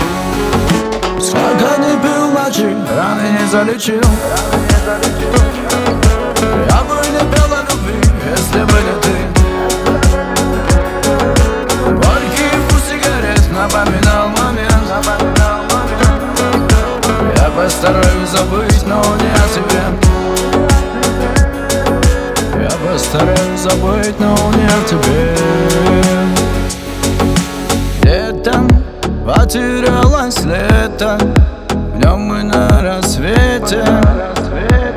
Жанр: Шансон / Русские